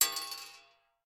Bullet Shell Sounds
pistol_metal_4.ogg